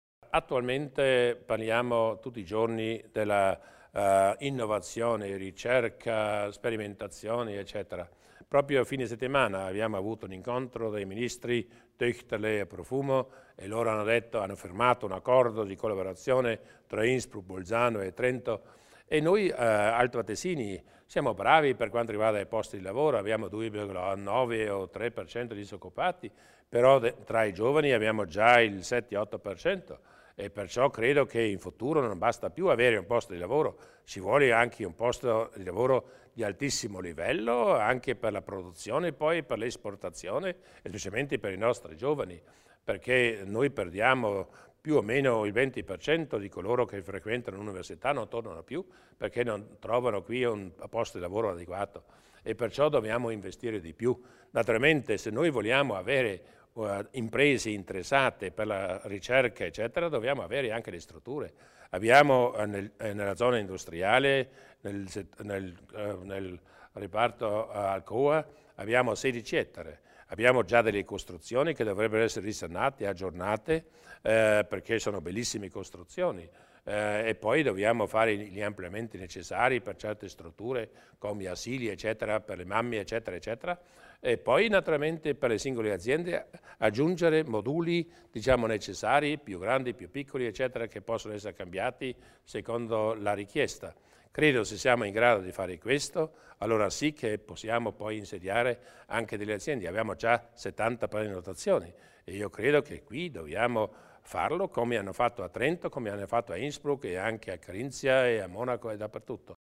Il Presidente Durnwalder spiega i prossimi passi per la realizzazione del polo tecnologico